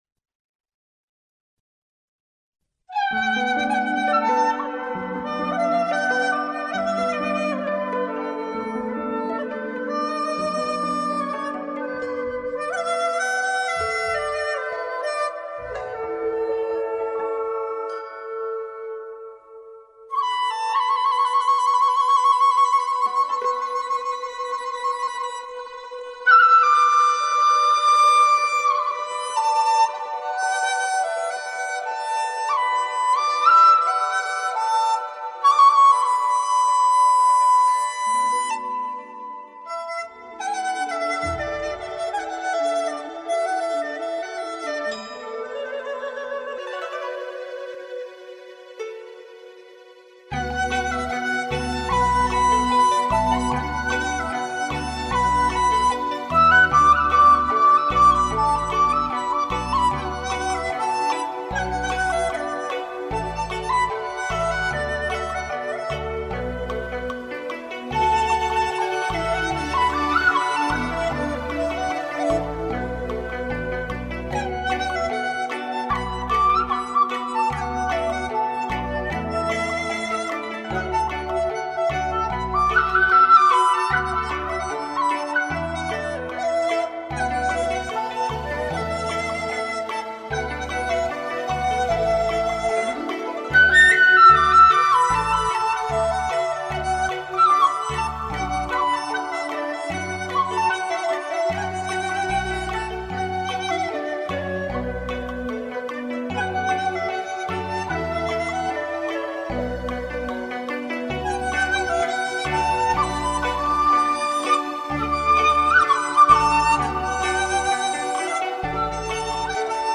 乐曲生动形象地描绘了天真活泼的牧童在问话对答时的明快情绪，风趣欢跃，展示了一幅江南水乡的民俗画卷。